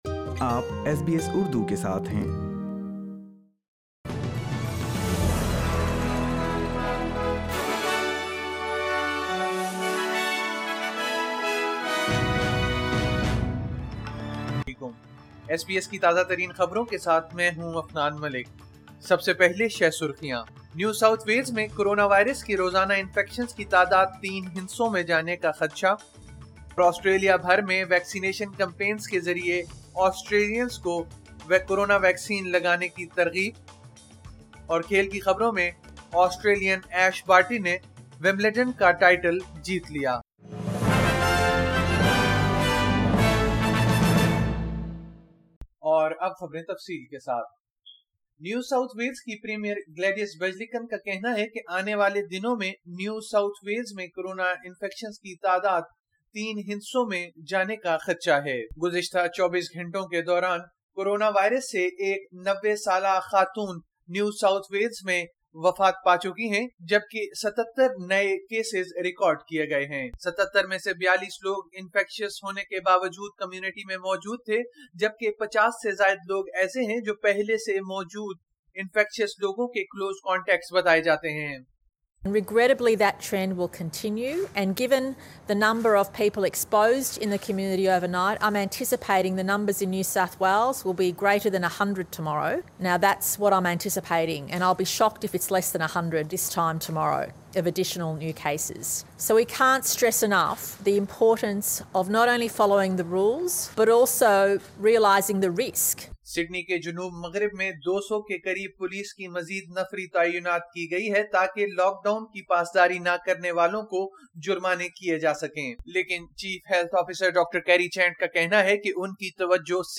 SBS Urdu News 11 July 2021